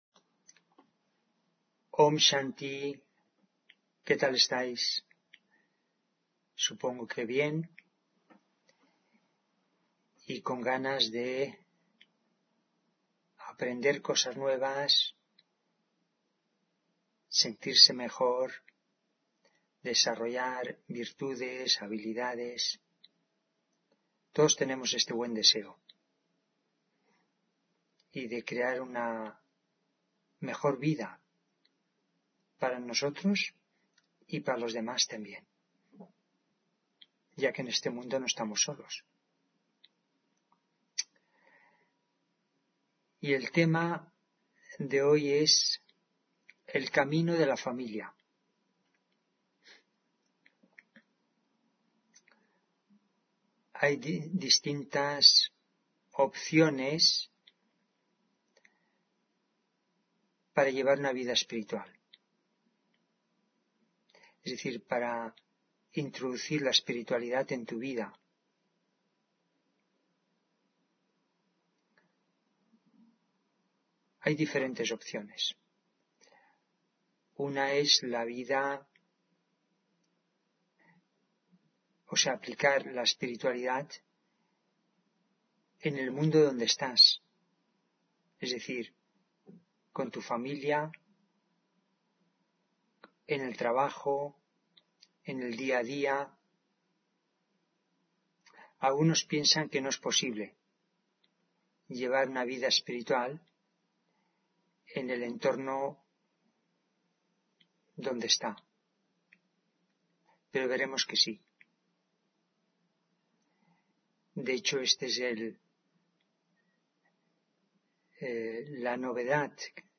Meditación de la mañana: El camino de la familia